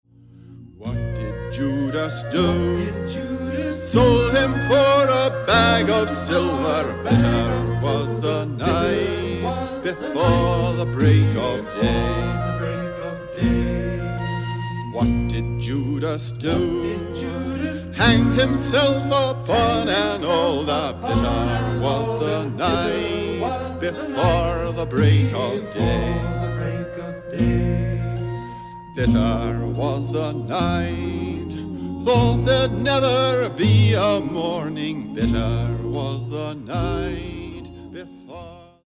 Spoken word, Vocal